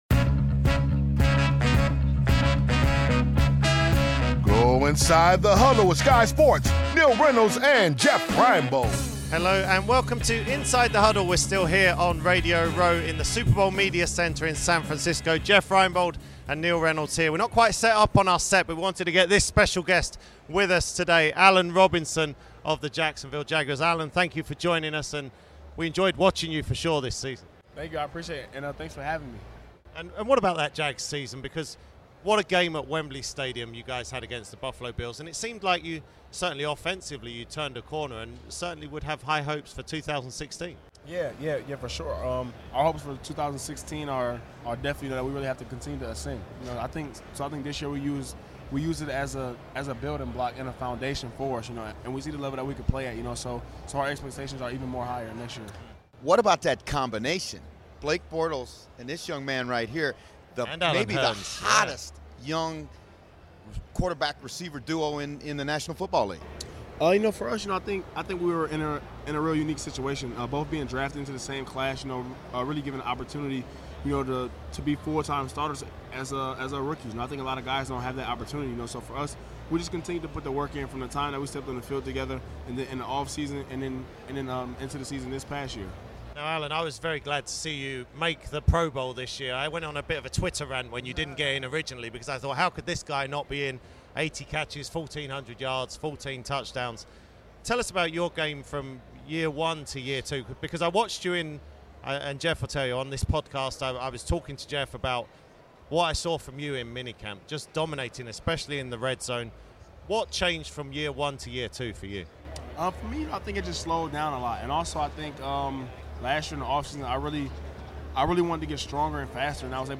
present another podcast from Super Bowl 50's Radio Row in San Francisco. In this episode the guys are joined by Jacksonsville Jaguars wide-reciever, Allen Robinson, four-time Super Bowl winning centre, Jesse Sapolu & Matt Birk, who won the Super Bowl with the Ravens.